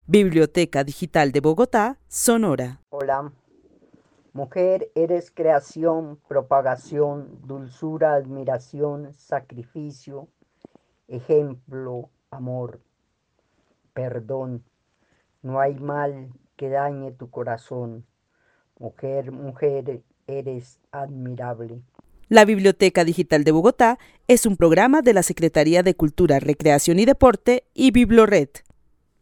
Narración oral acerca de lo que significa ser mujer para una habitante de Bogotá, a partir de cualidades como la dulzura, el amor, el perdón, el sacrificio y el ejemplo. El testimonio fue recolectado en el marco del laboratorio de co-creación "Postales sonoras: mujeres escuchando mujeres" de la línea Cultura Digital e Innovación de la Red Distrital de Bibliotecas Públicas de Bogotá - BibloRed.